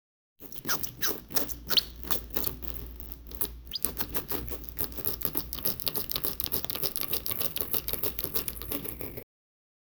the sound of a soft bun breaking 0:05 Spreading creamy frosting on sponge cake with spatula, soft squishy sound with knife 0:10 Soft cracking sound as the macaron is broken. 0:15 The sound of fingers breaking fresh cookie dough. 0:03
spreading-creamy-frosting-iiitlmn2.wav